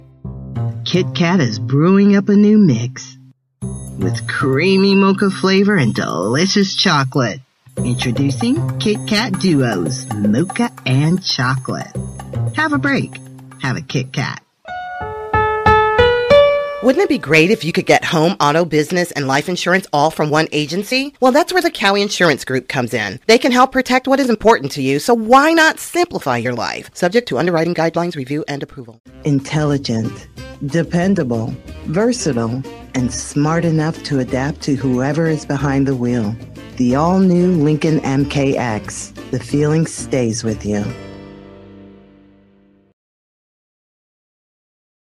Wonderland Voiceover Solutions delivers warm, engaging, professional voiceovers for commercials, narration, and more.